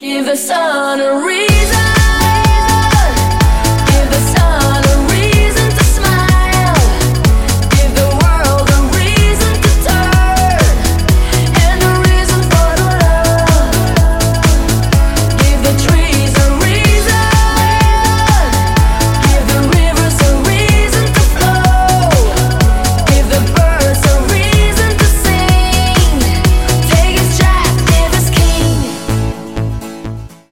• Качество: 128, Stereo
электроника